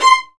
VIOLINS C6.wav